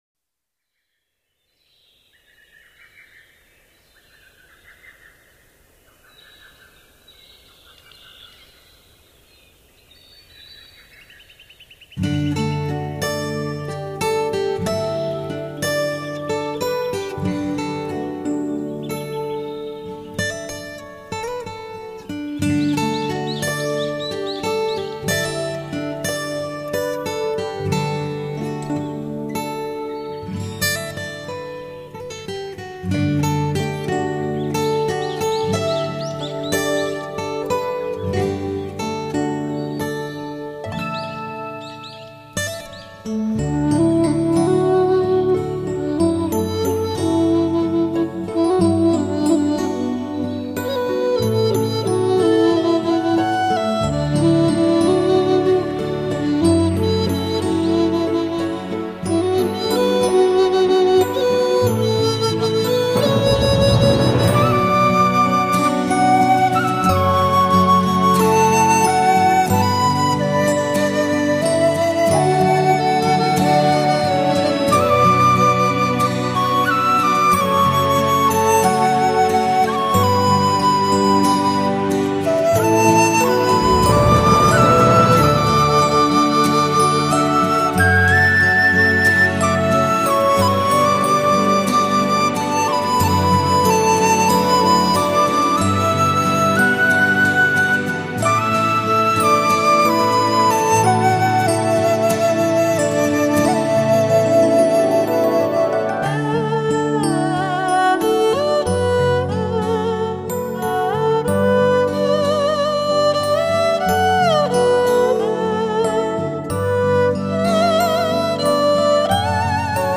东方音乐